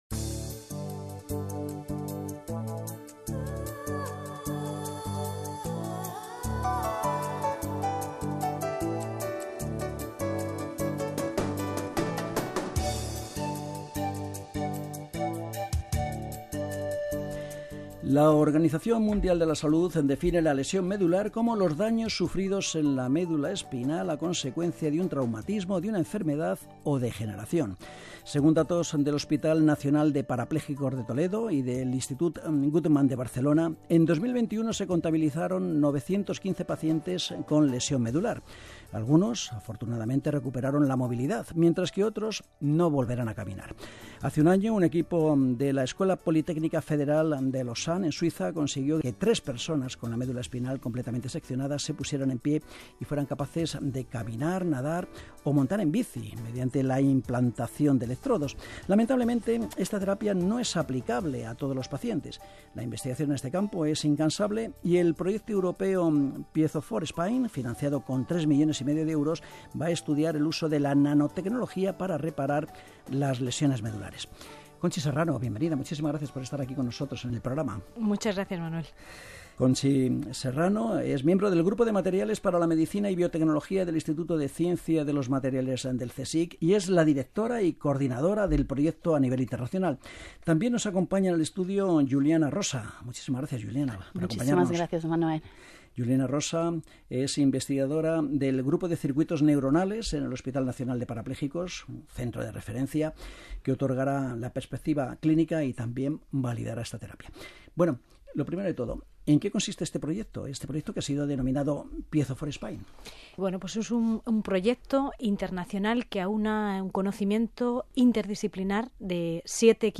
Radio Nacional de España (RNE) interviewed Piezo4Spine researchers!